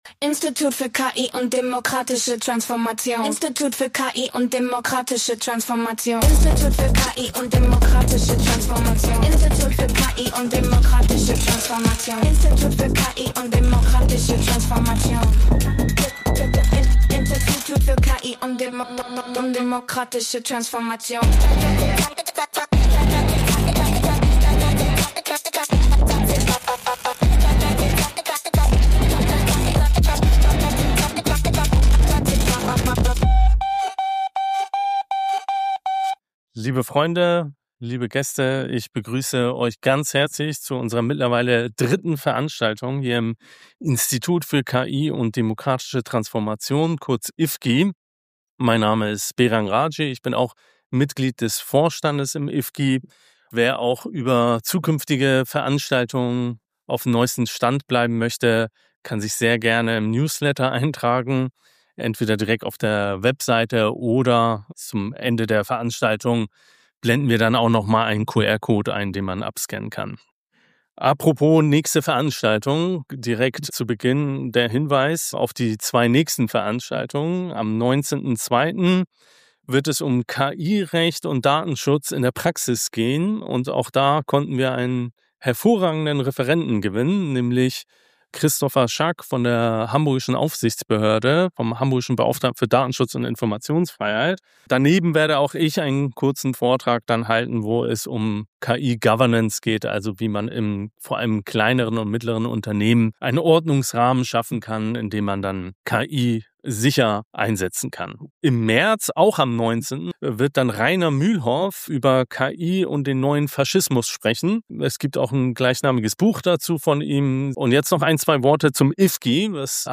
Mitschnitt der IfKI-Veranstaltung am 29.01.2026